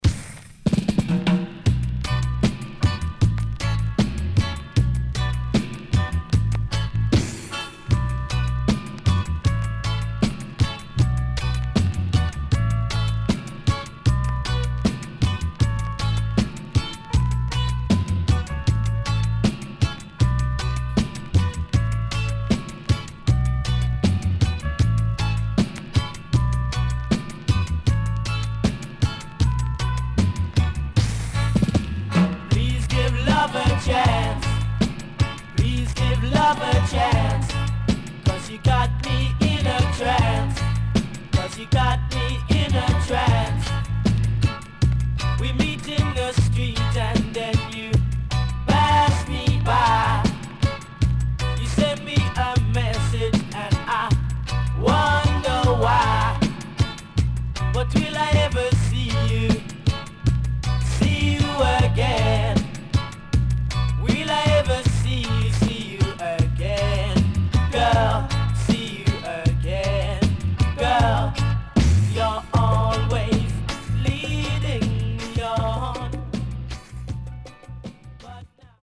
HOME > DANCEHALL  >  EARLY 80’s  >  定番DANCEHALL
CONDITION SIDE A:VG(OK)
SIDE A:所々チリノイズがあり、少しプチノイズ入ります。